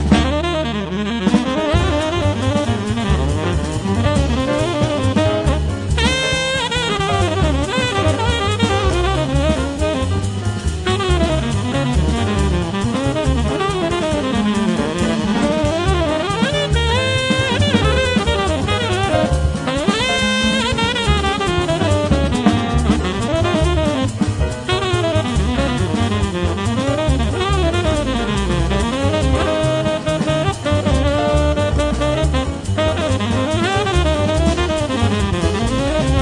The Best In British Jazz
Recorded Red Gable Studio, London 31st August 2006